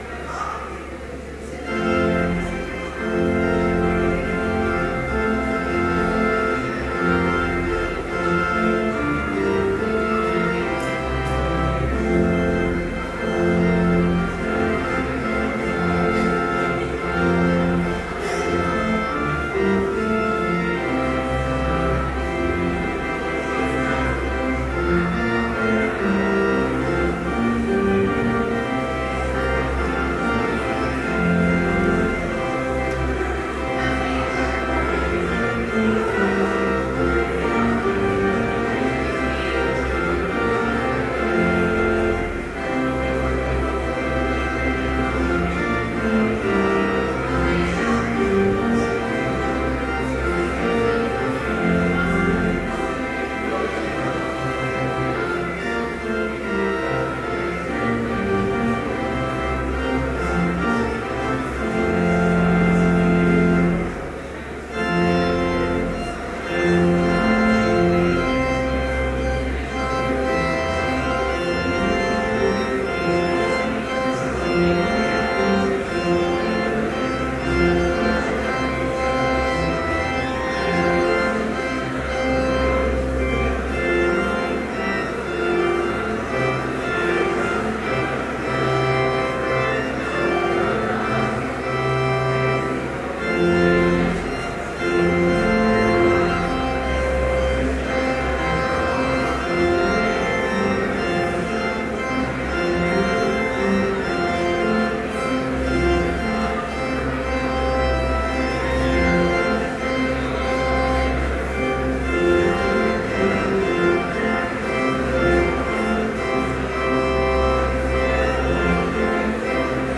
Complete service audio for Chapel - October 25, 2013
Hymn 511, vv. 1-4, Lord Jesus Christ, with Us Abide
Homily